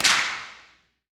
Claps
Gang Clap.wav